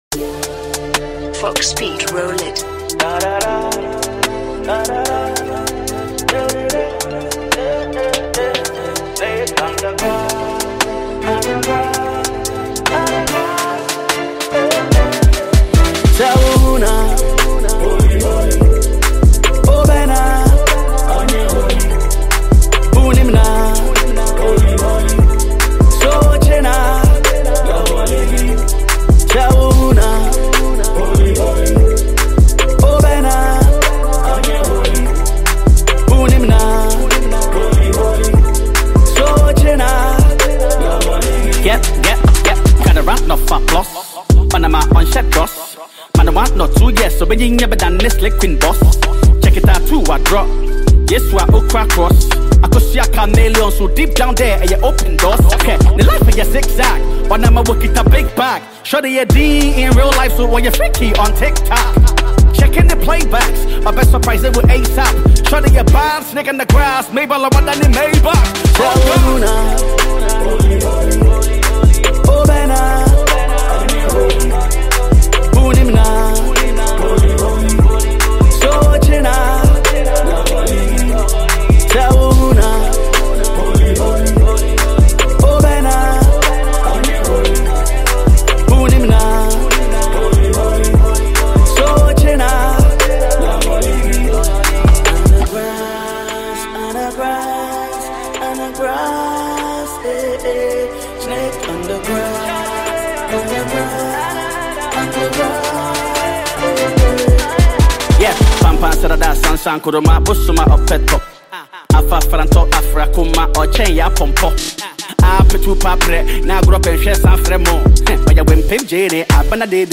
Ghana Music
a deep, worshipful sentiment
sophisticated Afro-Gospel fusion